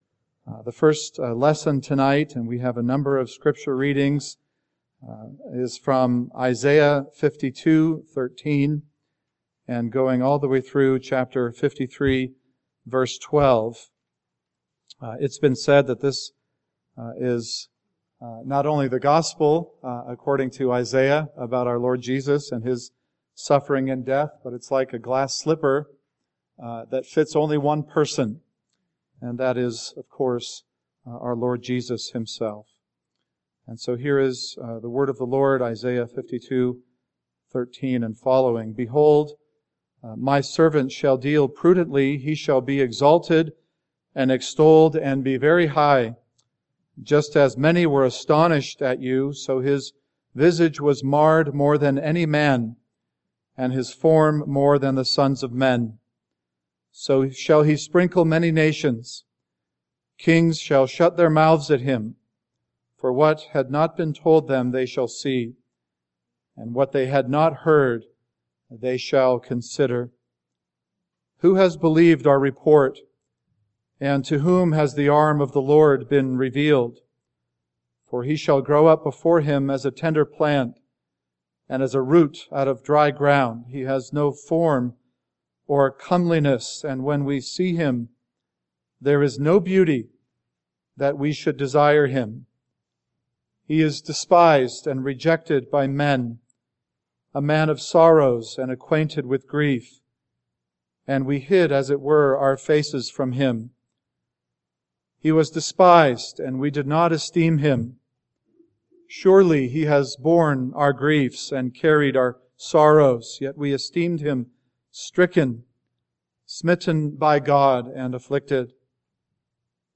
Good Friday Sermon